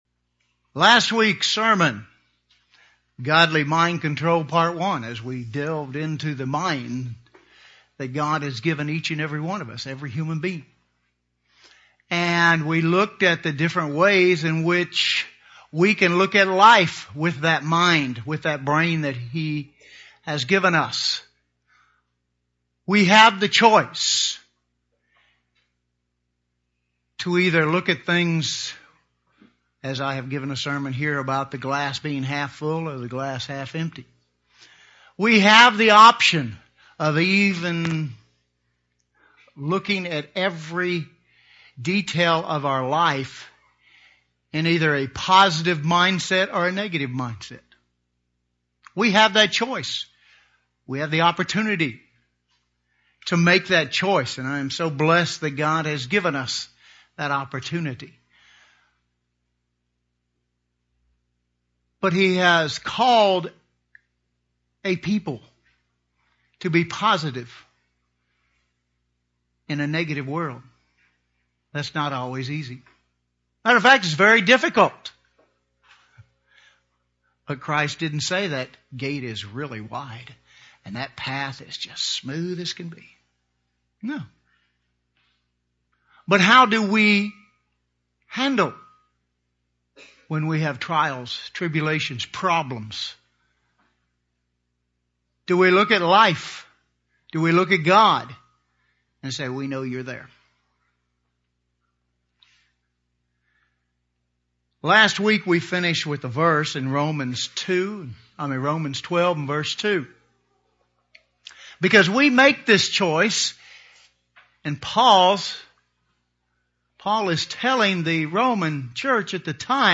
Last week's sermon, Godly Mind Control Part 1, as we delved into the mind that God has given each and every one of us, every human being.